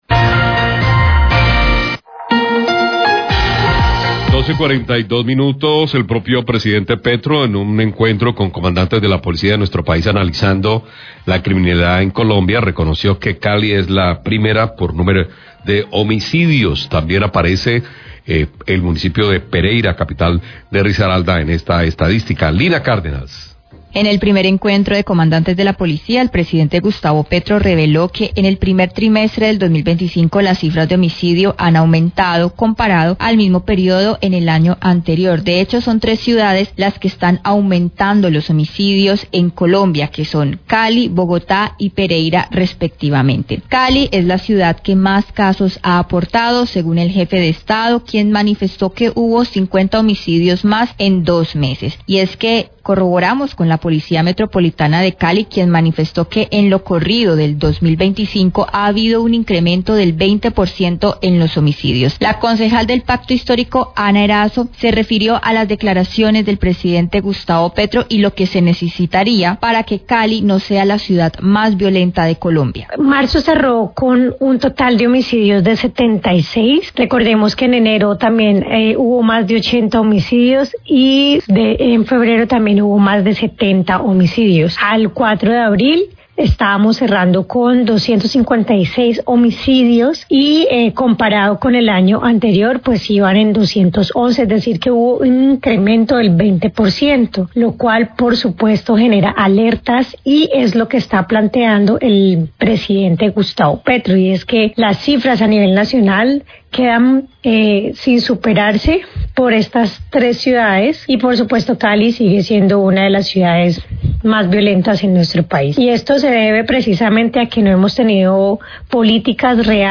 NOTICIERO RELÁMPAGO